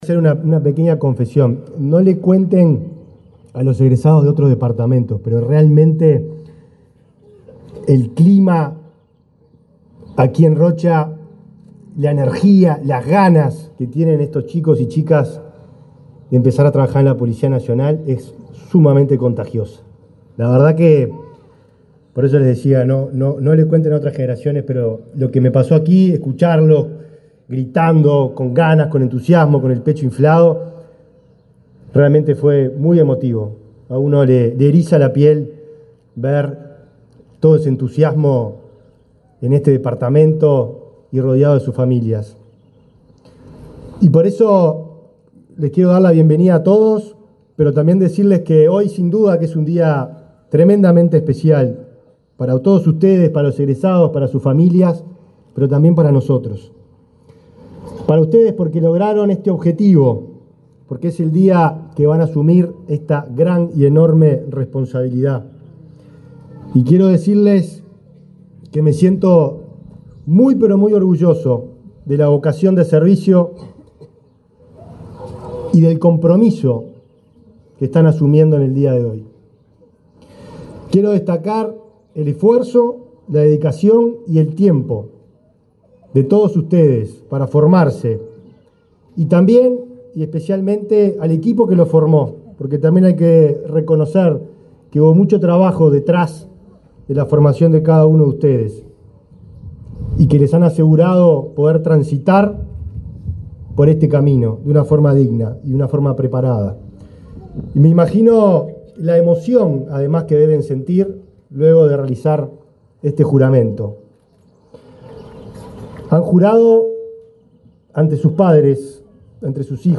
Palabras del ministro del Interior, Nicolás Martinelli
El ministro del Interior, Nicolás Martinelli, encabezó el acto de egreso de 64 alumnos del 89.°curso de formación de la escala básica de policía de la